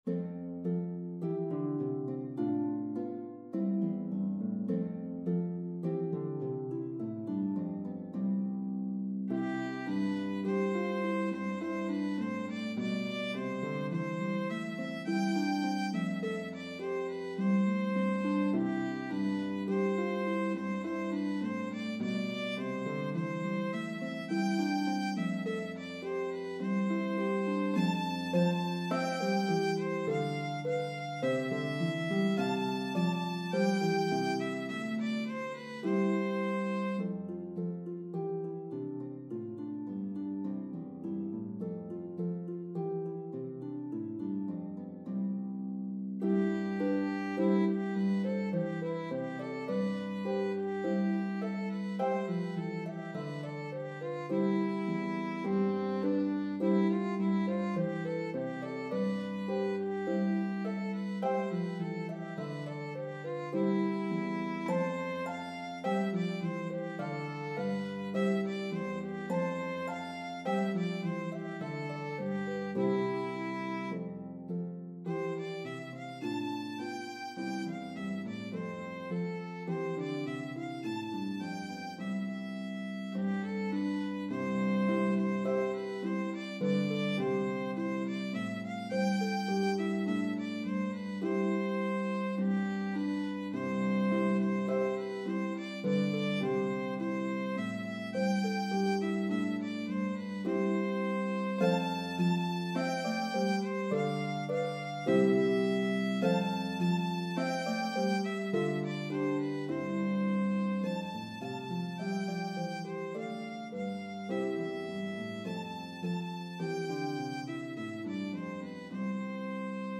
This arrangement has 4 verses separated by interludes.